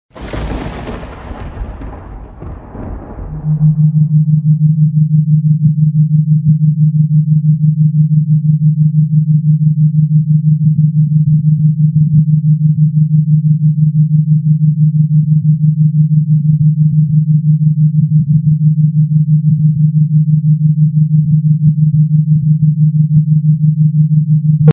6Hz Binaural Beats = Theta sound effects free download